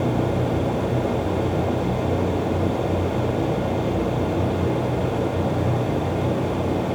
background_fan_air_vent_loop_01.wav